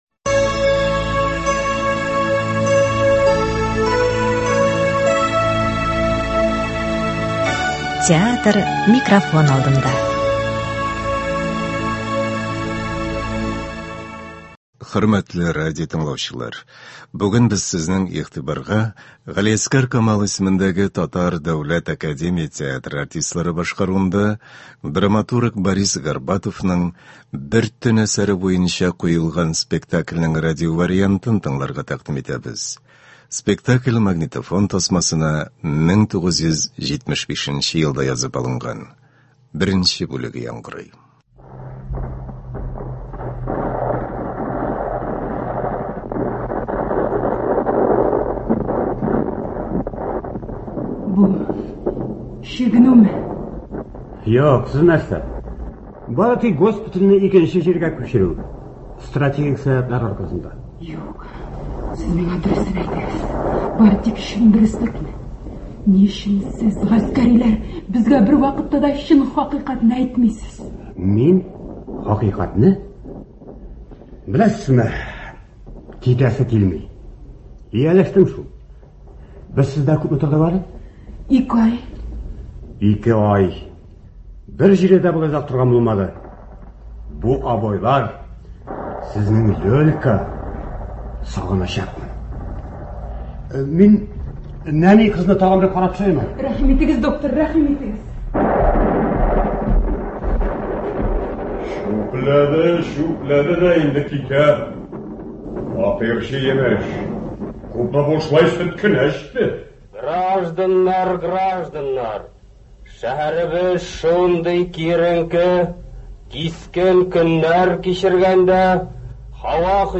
Борис Горбатов. “Бер төн”. Г.Камал исемендәге ТДАТ спектакленең радиоварианты.
Спектакльнең режиссеры – Марсель Сәлимҗанов. Спектакль магнитофон тасмасына 1975 нче елда язып алынган.